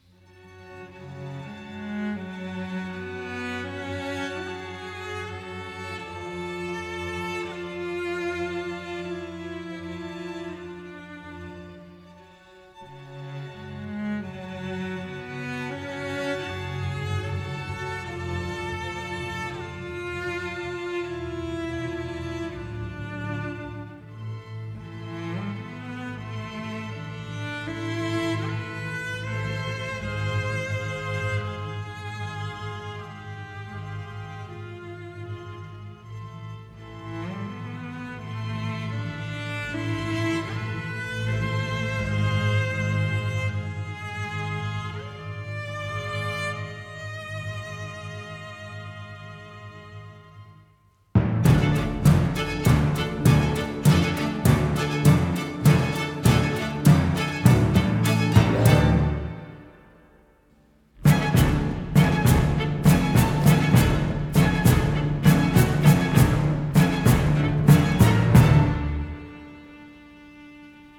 conducts a large orchestra recorded in Los Angeles.